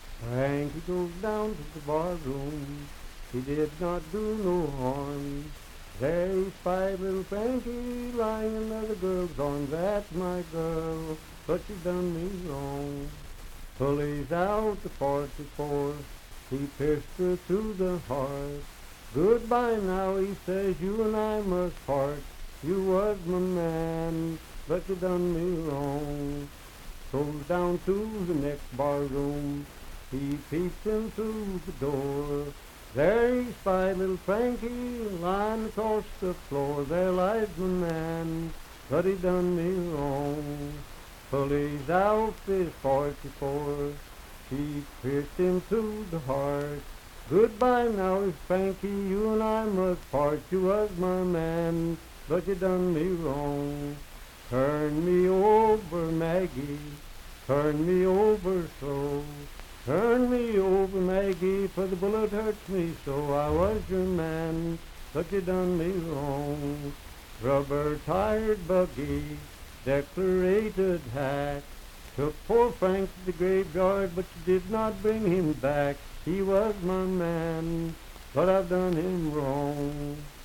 Unaccompanied vocal music
Voice (sung)
Franklin (Pendleton County, W. Va.), Pendleton County (W. Va.)